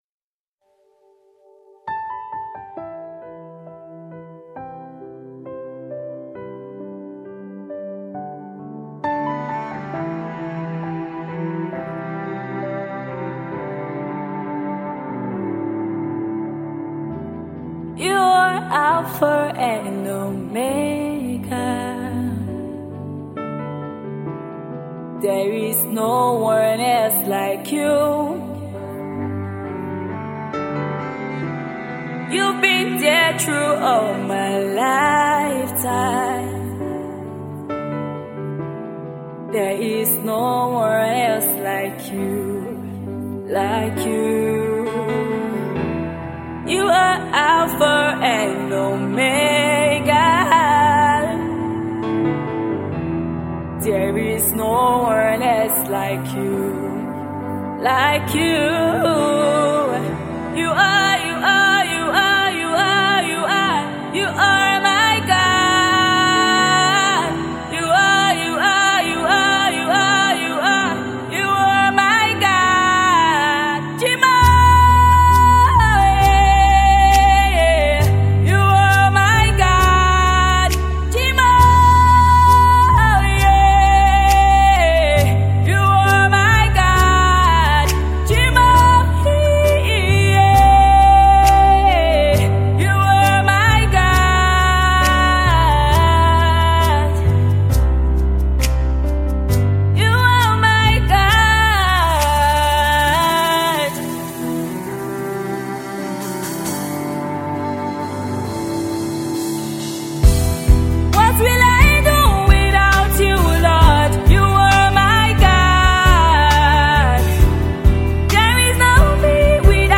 Versatile gospel music minister
it has elements of praise and worship